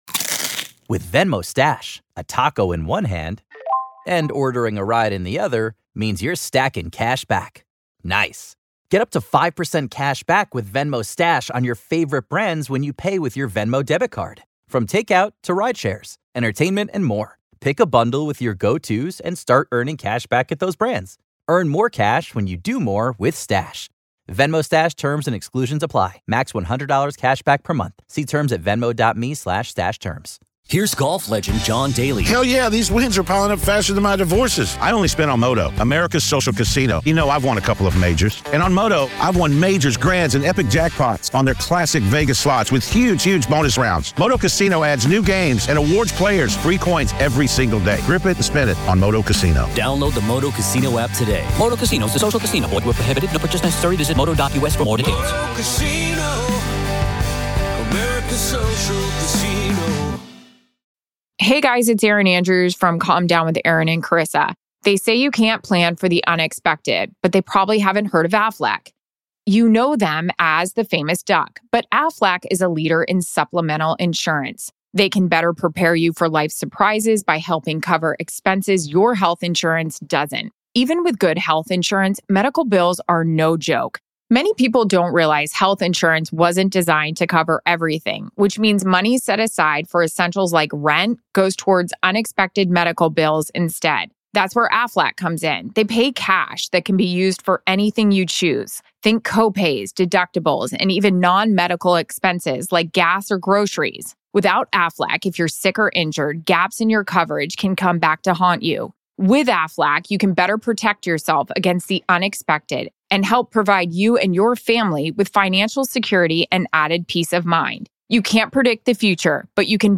Interview Part 1